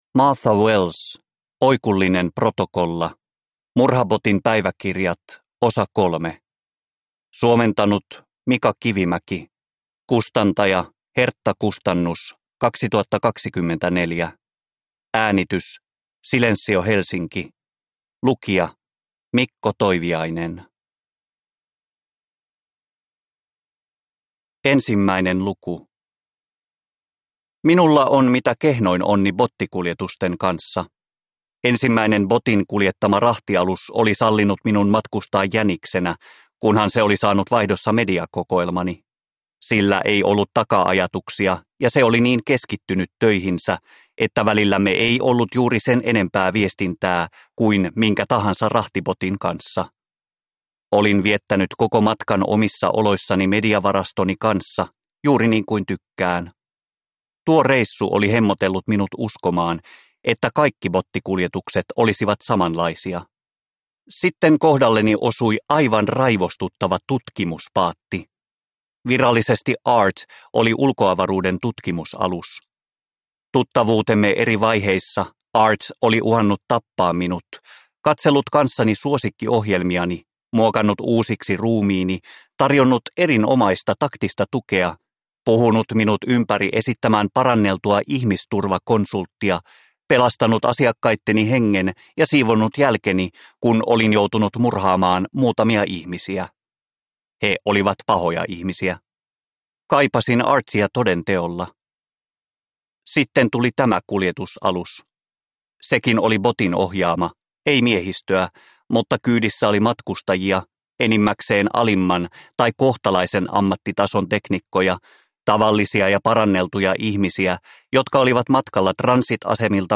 Oikullinen protokolla – Ljudbok